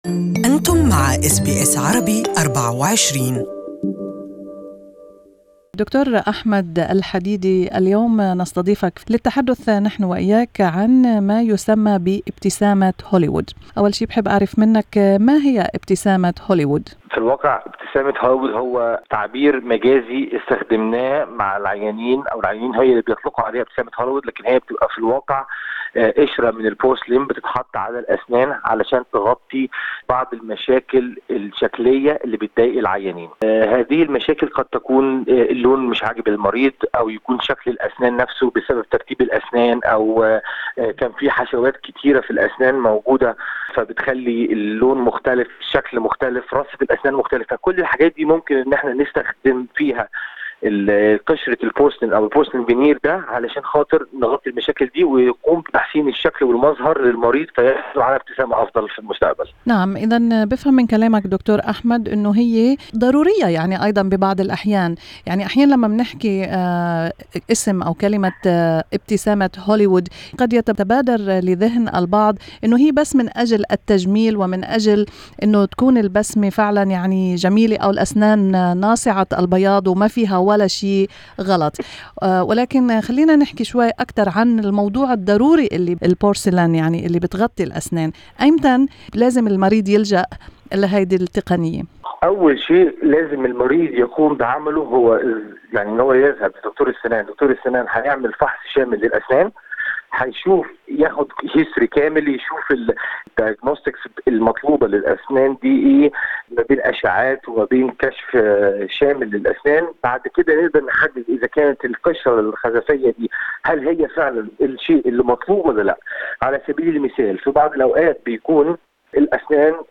استمعوا الى اللقاء كاملا تحت الرابط الصوتي.